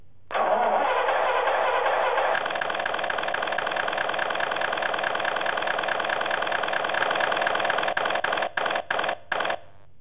The engine sounds are load sensitive being loud when accelerating, softened when coasting and softer still when slowing down.
light_engine_small_simplex.wav